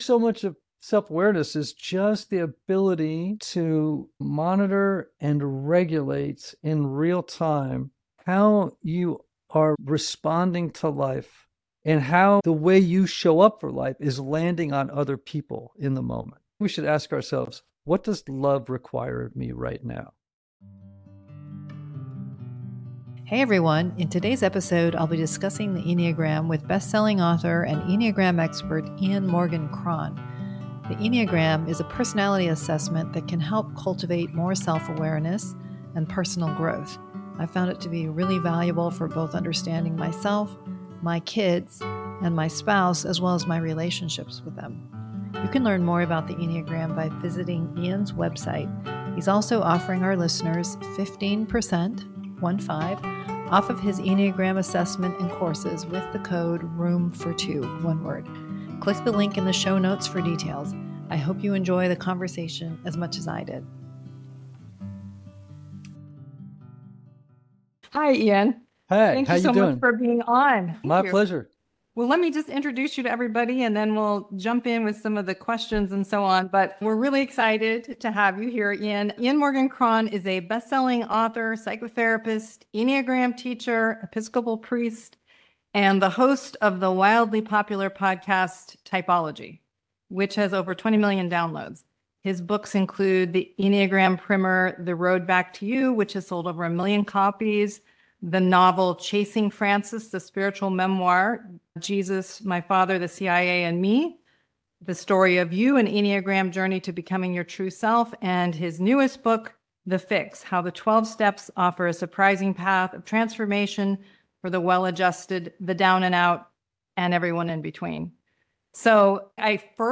Understanding the Enneagram | Q&A with Ian Morgan Cron